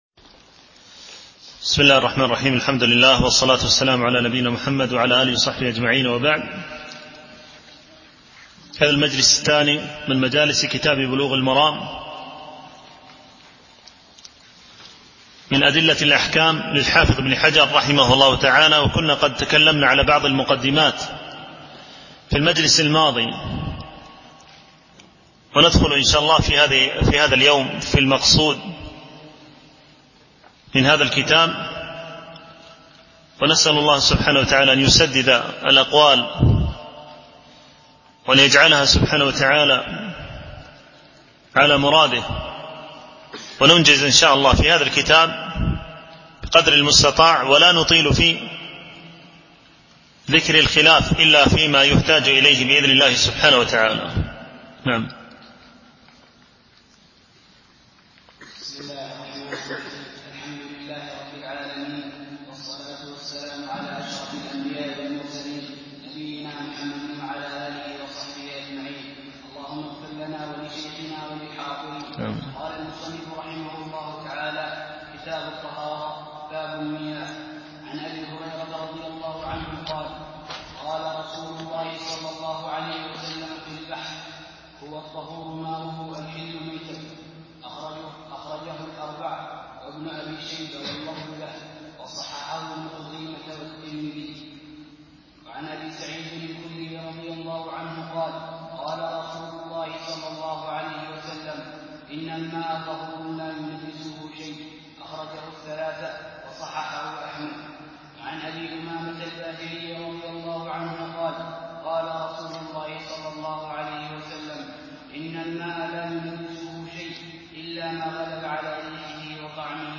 شرح كتاب بلوغ المرام من أدلة الأحكام - الدرس 2 (كتاب الطهارة، الحديث 1-7)